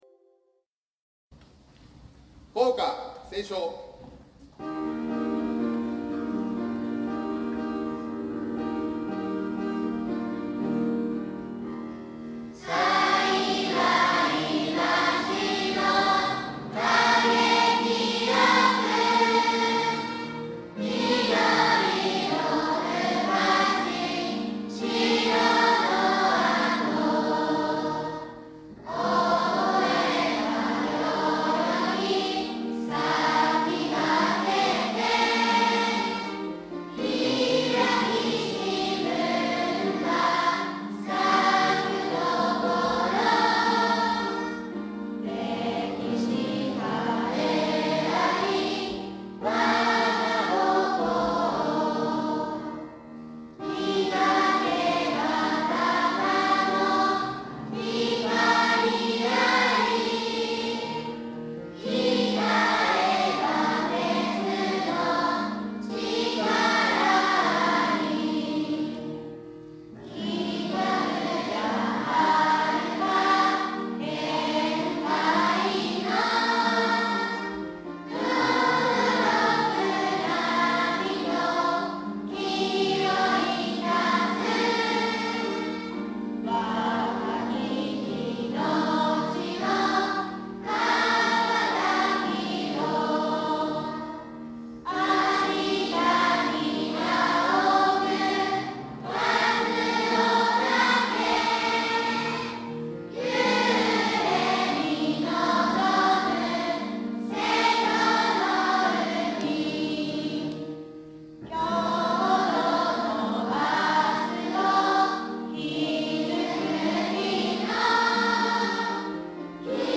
子供たちが校歌を歌っています(2199KB)